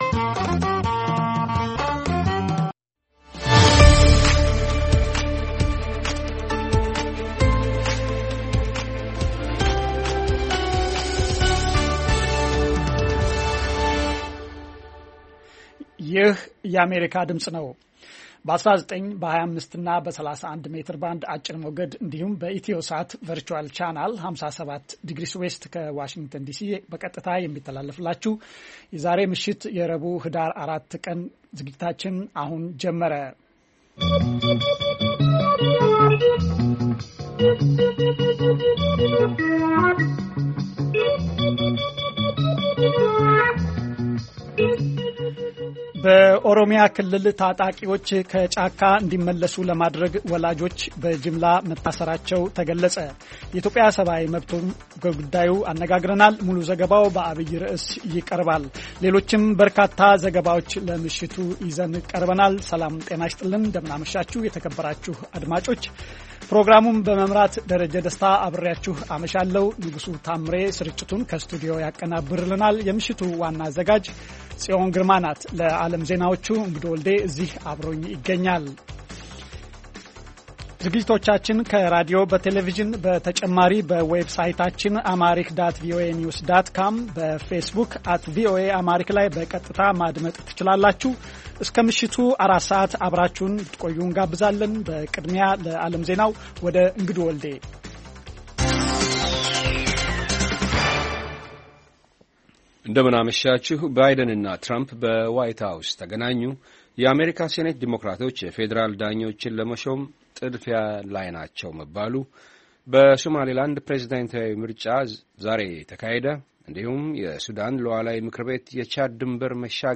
ቪኦኤ በየዕለቱ ከምሽቱ 3 ሰዓት በኢትዮጵያ አቆጣጠር ጀምሮ በአማርኛ፣ በአጭር ሞገድ 22፣ 25 እና 31 ሜትር ባንድ የ60 ደቂቃ ሥርጭቱ ዜና፣ አበይት ዜናዎች ትንታኔና ሌሎችም ወቅታዊ መረጃዎችን የያዙ ፕሮግራሞች ያስተላልፋል። ረቡዕ፡- ዴሞክራሲ በተግባር፣ ሴቶችና ቤተሰብ፣ አሜሪካና ሕዝቧ፣ ኢትዮጵያዊያን ባሜሪካ